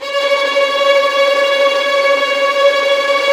Index of /90_sSampleCDs/Roland LCDP13 String Sections/STR_Violins Trem/STR_Vls Trem wh%